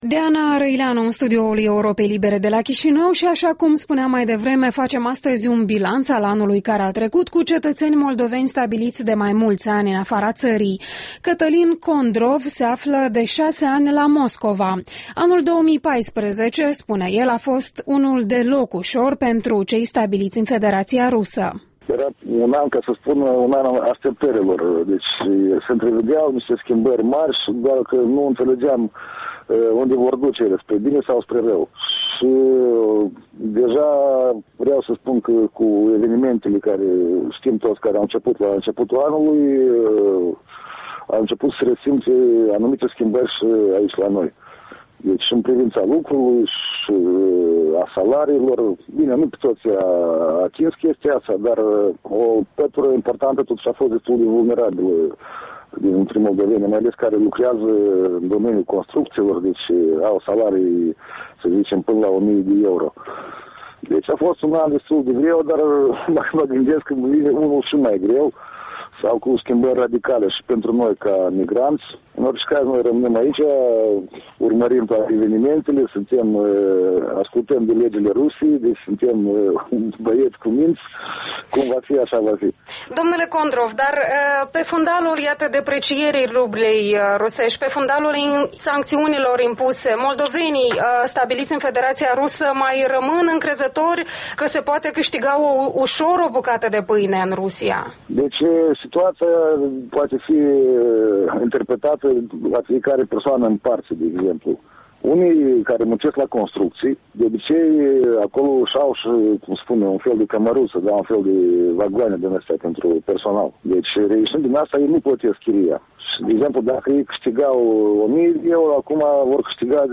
Interviurile dimineții